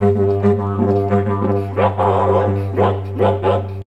TALKING ST.wav